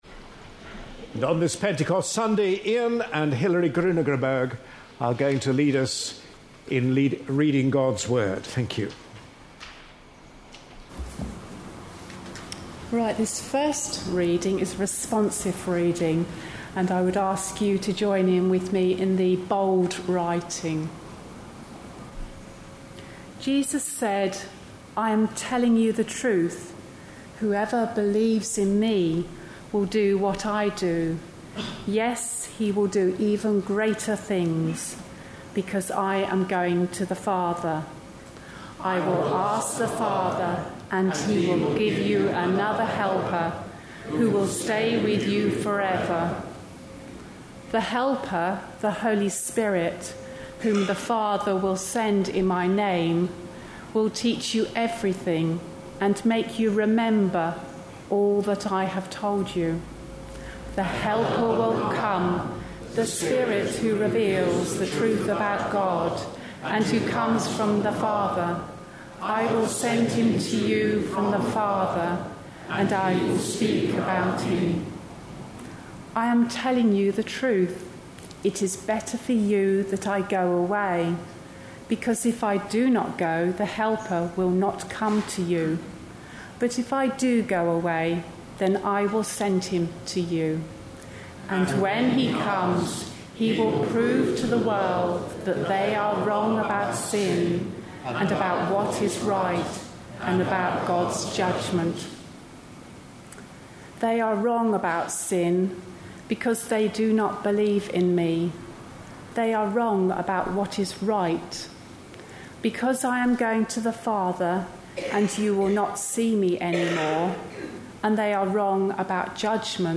A sermon preached on 27th May, 2012, as part of our Life in The Spirit series.